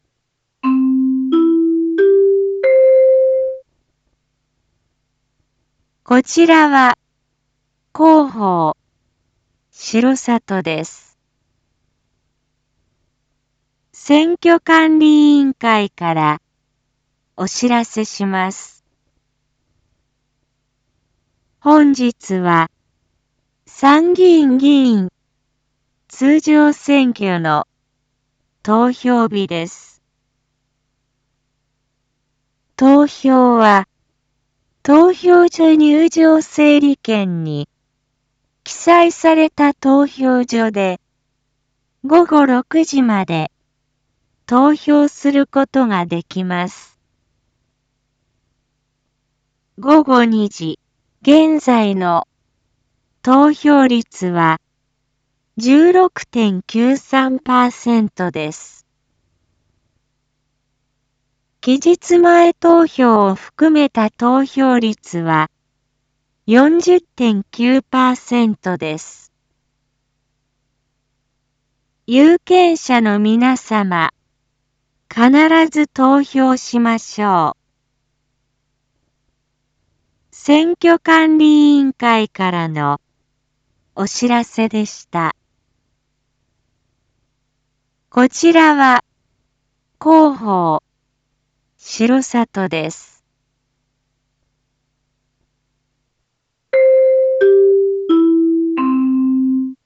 Back Home 一般放送情報 音声放送 再生 一般放送情報 登録日時：2022-07-10 14:16:41 タイトル：R4.7.10 参議院議員通常選挙（午後2時投票状況） インフォメーション：こちらは広報しろさとです。